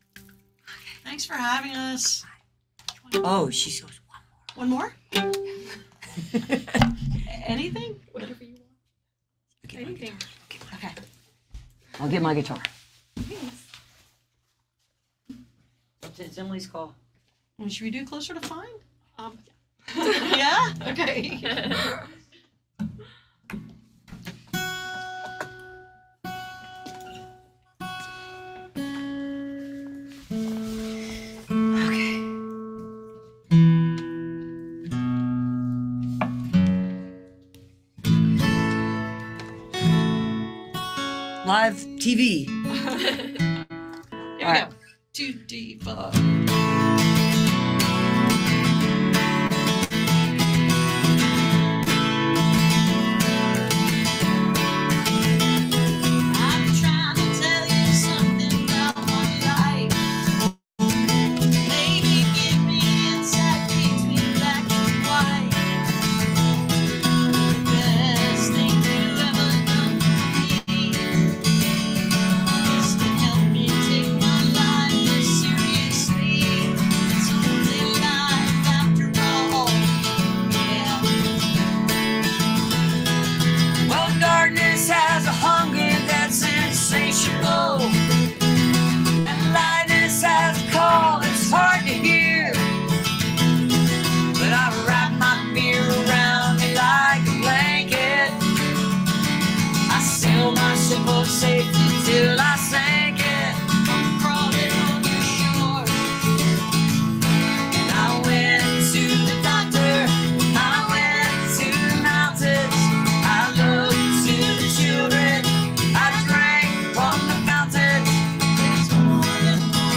(captured from the live streams)